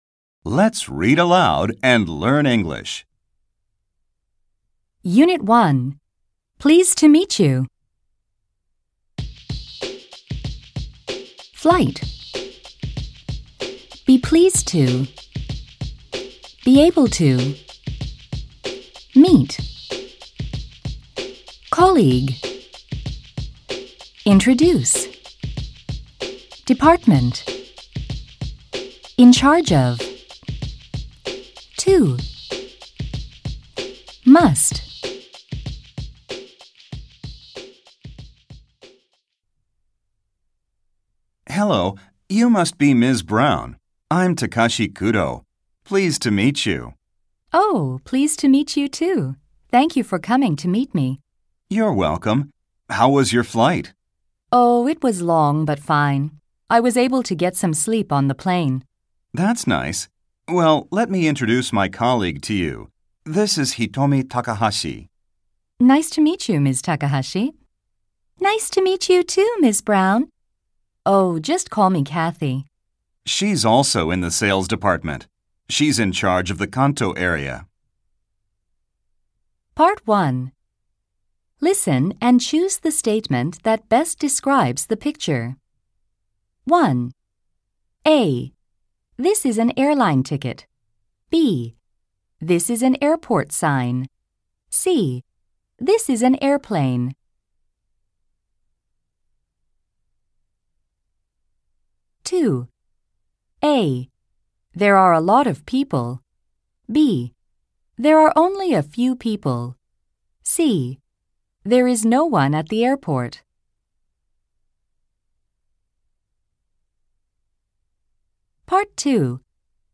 Audio Language Amer E